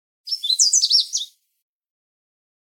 Song of the Canada Warbler
220-e-canada-type-a3.mp3